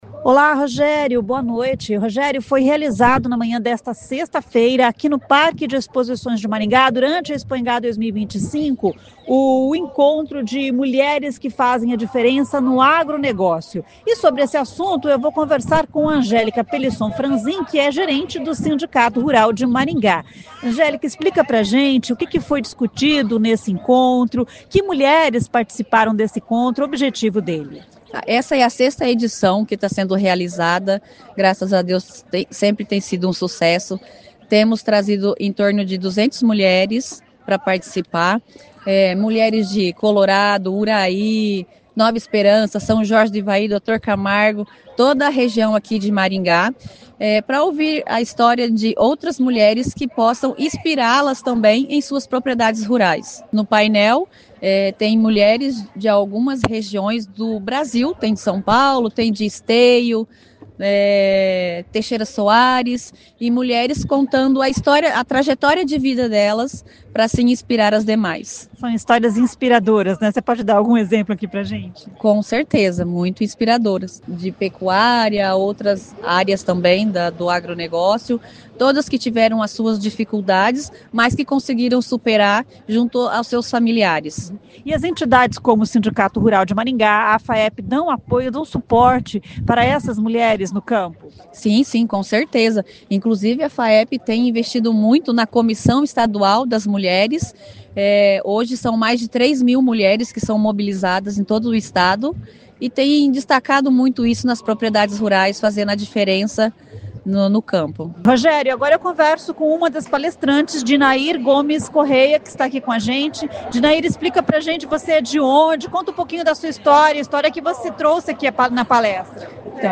Foi realizado nesta sexta-feira (16) no Parque de Exposições de Maringá, durante a Expoingá 2025, o "Encontro de mulheres que fazem a diferença no agronegócio". Palestrantes de vários estados participaram.